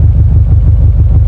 Buggy_Idle.wav